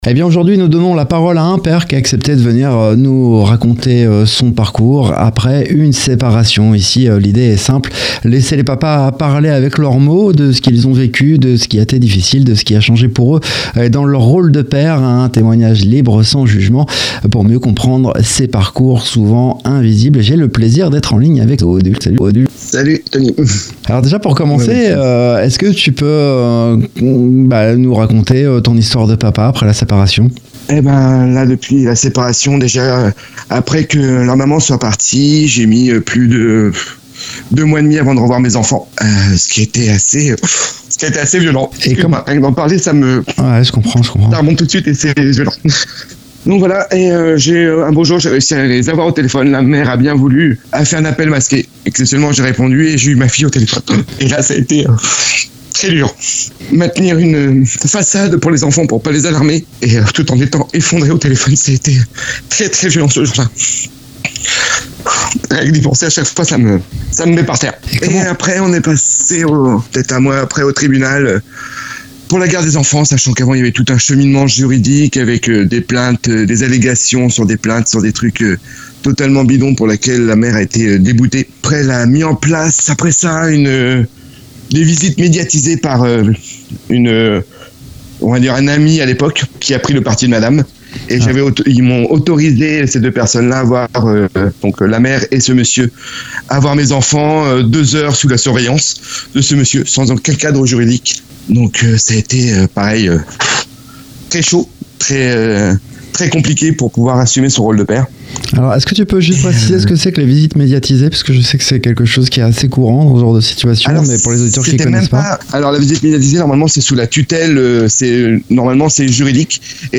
Mais il parle aussi de ce qui le fait tenir : l’amour pour ses enfants et la volonté de préserver ce lien malgré les obstacles. Un témoignage